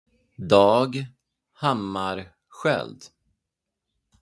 For an authentic Swedish pronunciation,
DH-first-and-last-name-slow-version.mp3